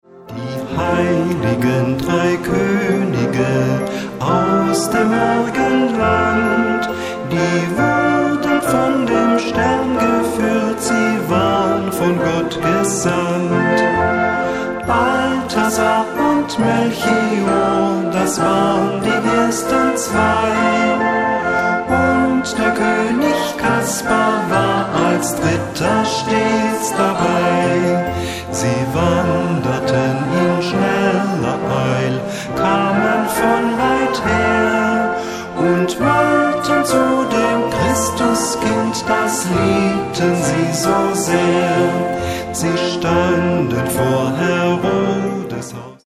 A-B Besetzung: Blasorchester Zu hören auf